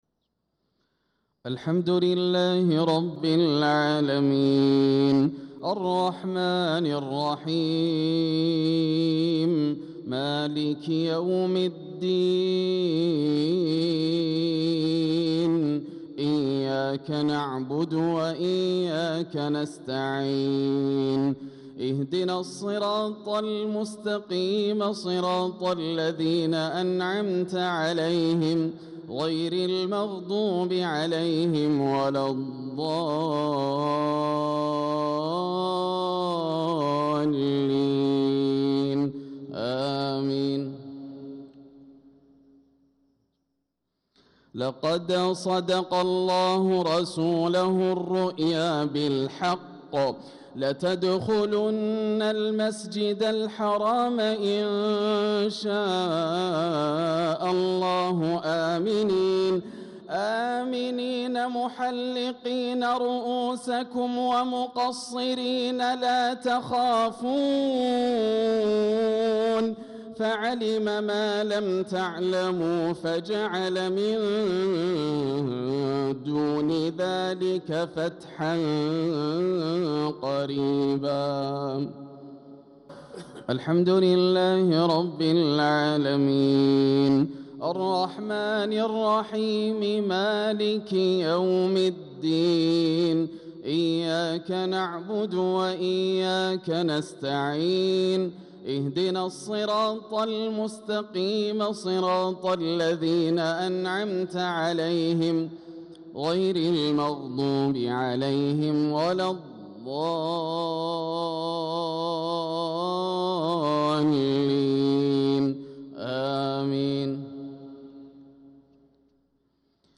صلاة المغرب للقارئ ياسر الدوسري 8 ذو الحجة 1445 هـ
تِلَاوَات الْحَرَمَيْن .